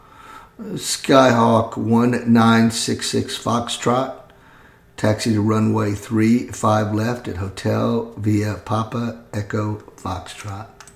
Aviation Radio Calls
07_GroundRunwayThreeFiveLeftAtHotel.mp3